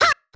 TS Chant 5.wav